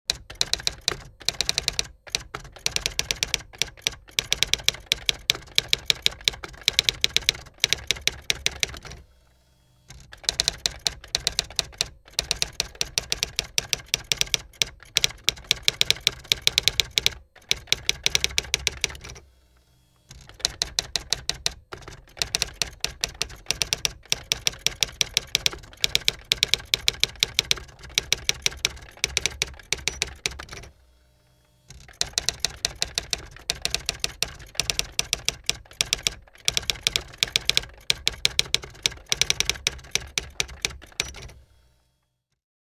typewriter.mp3